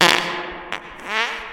toot_echo.ogg